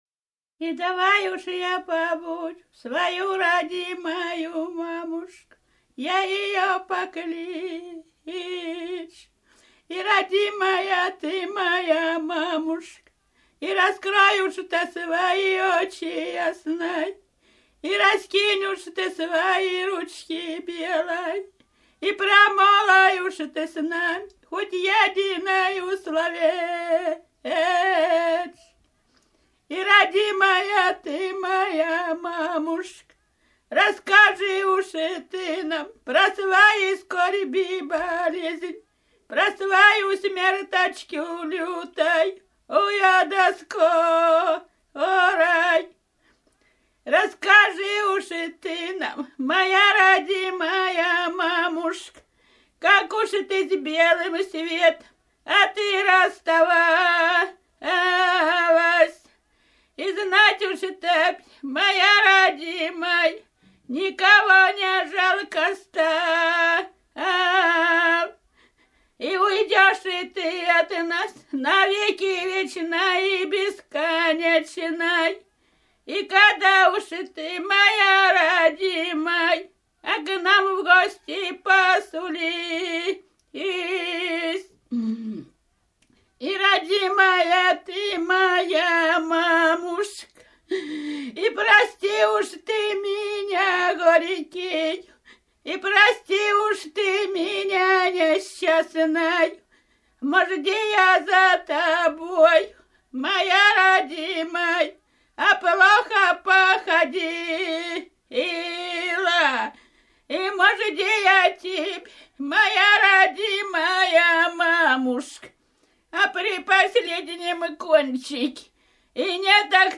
Рязань Кутуково «И давай уже повою», похоронный плач.